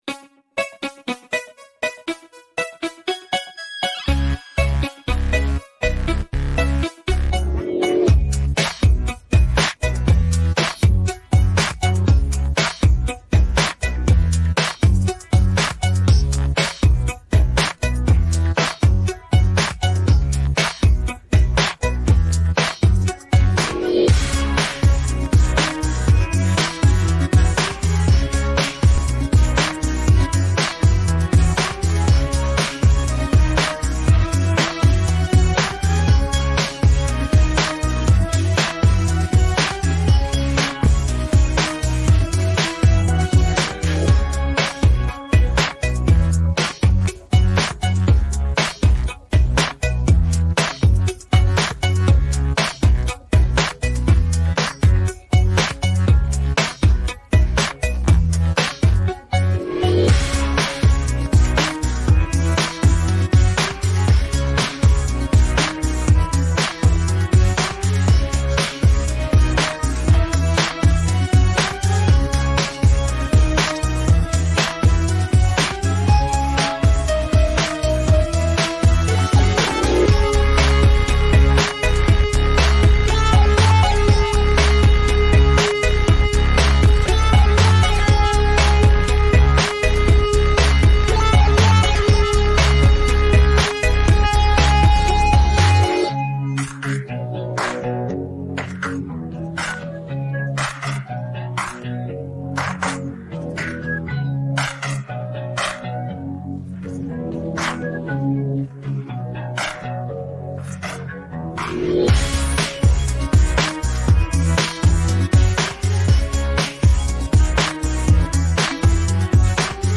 pop караоке 63